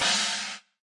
cymbals/crashes » crash china cymbal 2
标签： crash china cymbal
声道立体声